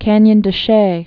(kănyən də shā)